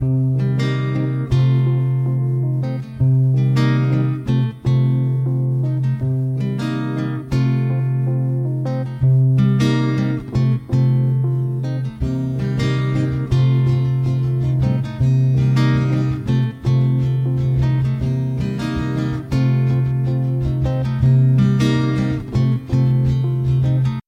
160 Bpm 木吉他
Tag: 160 bpm Jazz Loops Guitar Acoustic Loops 4.05 MB wav Key : C